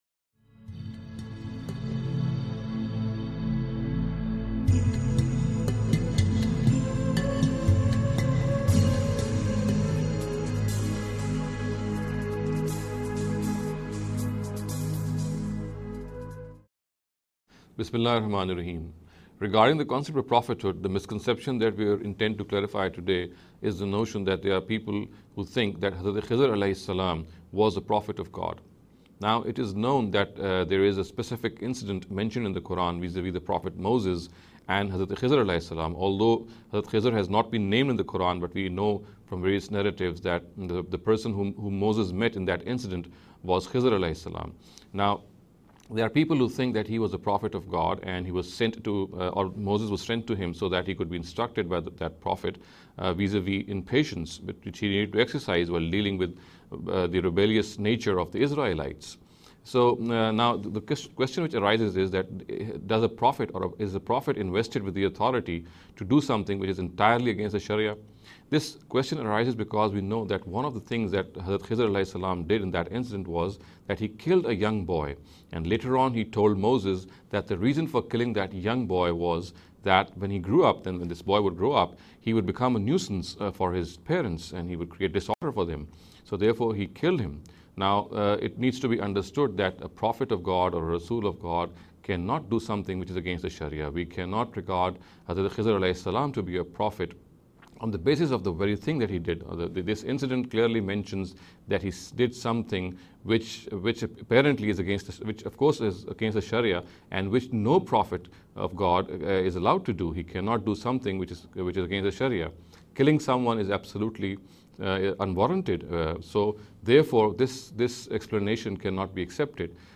In this series of short talks